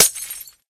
glass1.ogg